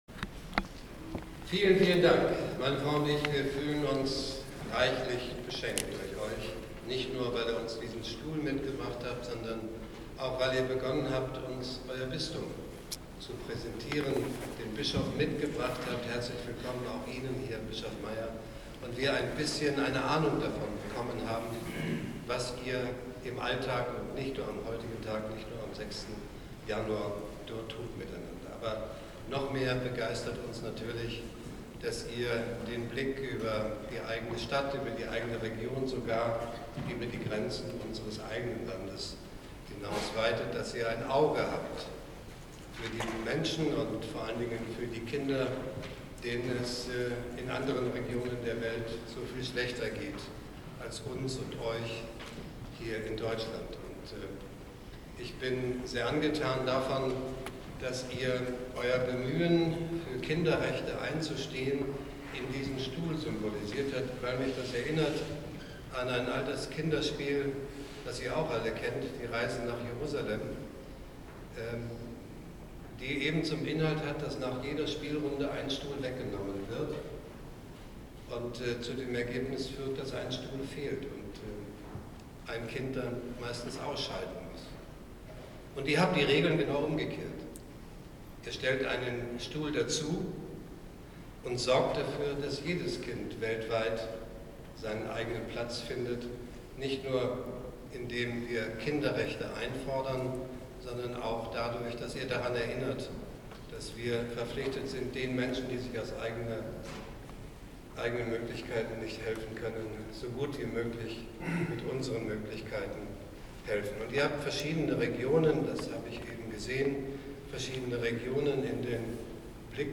Rede: Bundespräsident Frank-Walter Steinmeier empfing kleine und große Könige aus dem Bistum Augsburg.
Bundespraesident_Rede.mp3